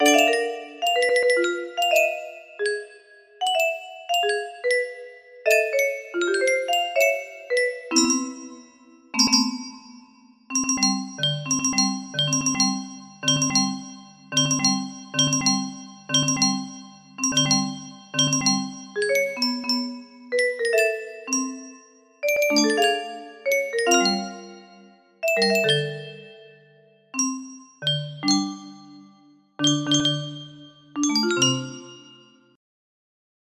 A mess music box melody